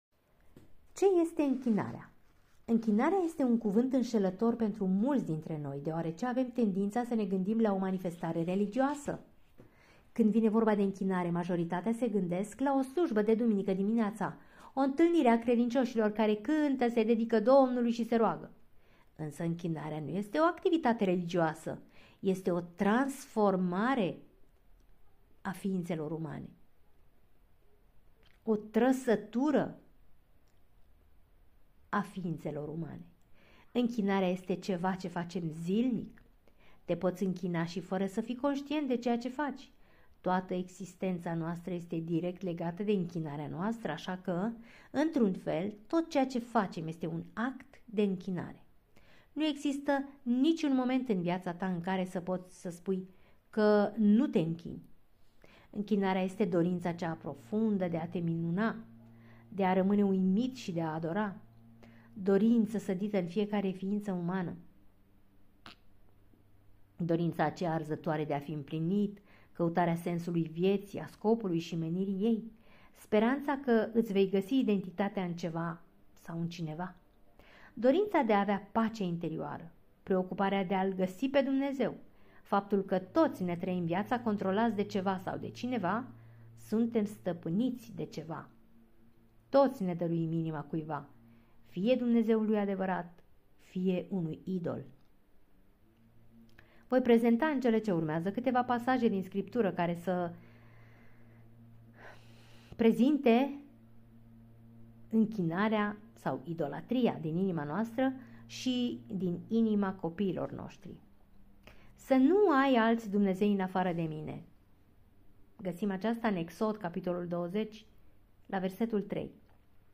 Capitolul este citit